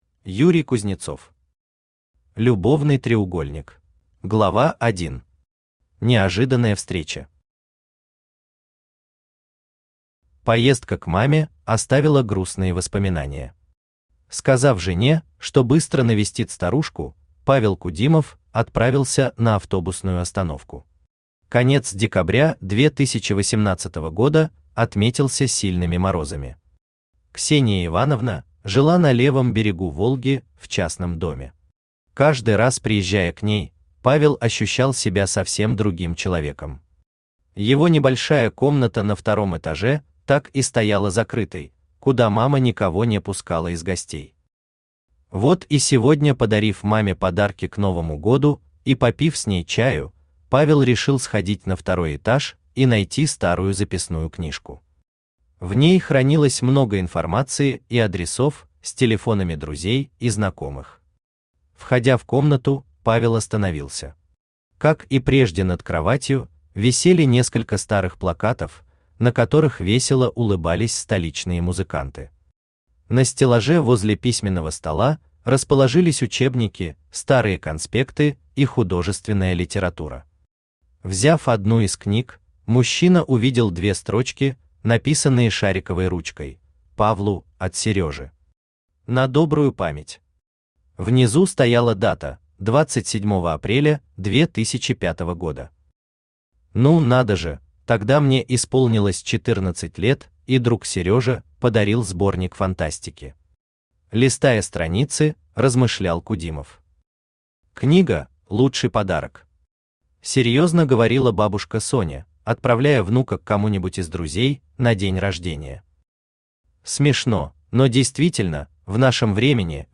Аудиокнига Любовный треугольник | Библиотека аудиокниг
Aудиокнига Любовный треугольник Автор Юрий Юрьевич Кузнецов Читает аудиокнигу Авточтец ЛитРес.